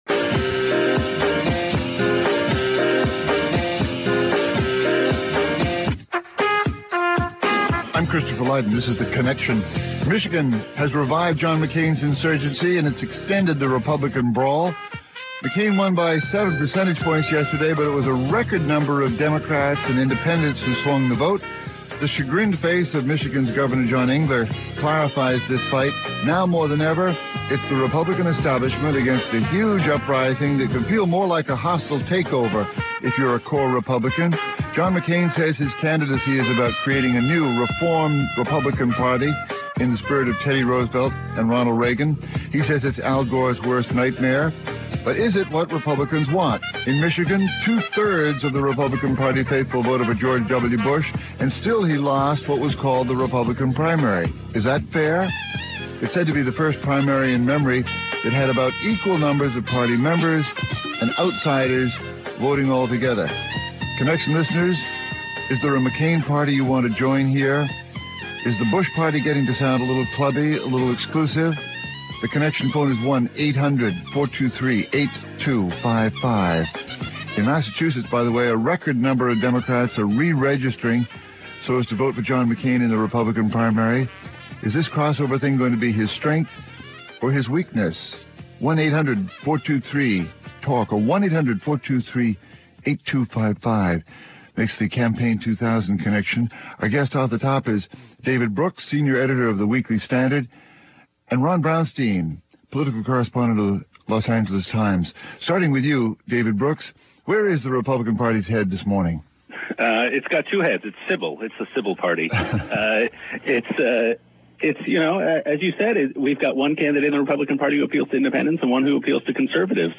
(Hosted by Christopher Lydon) Guests: